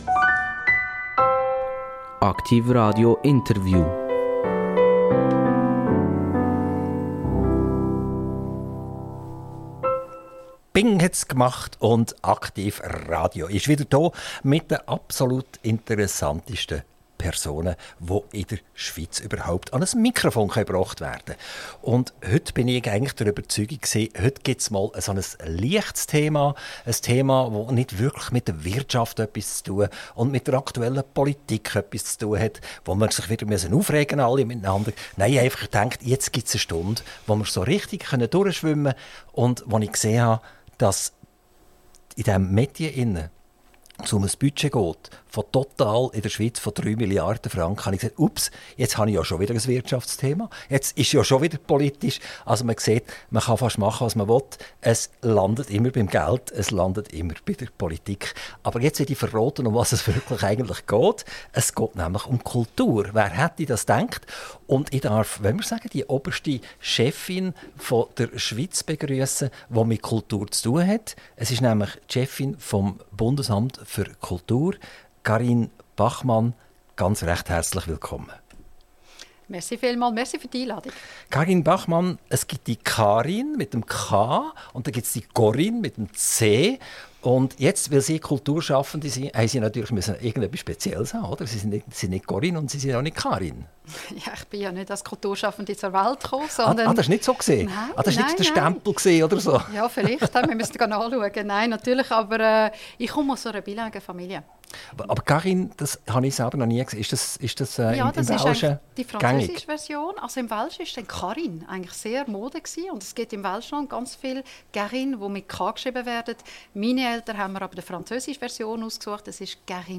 INTERVIEW - Carine Bachmann - 13.06.2024 ~ AKTIV RADIO Podcast